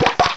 cry_not_crabrawler.aif